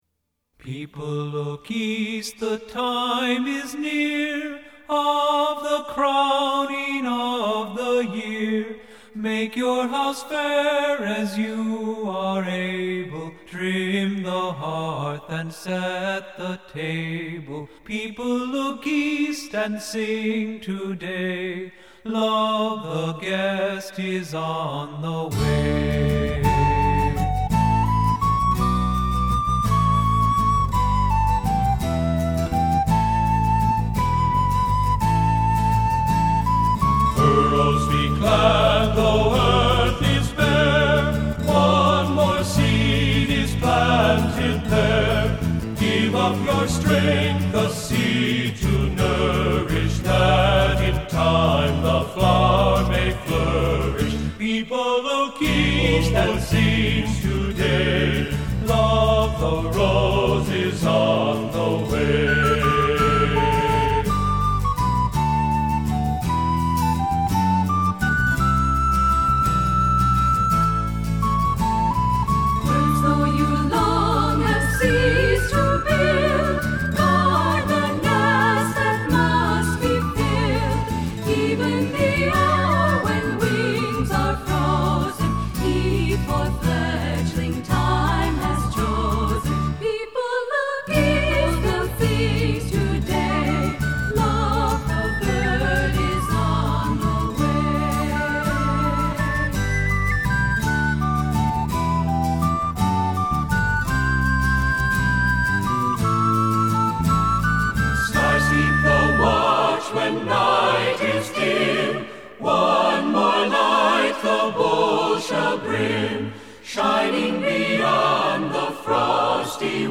Voicing: "SATB"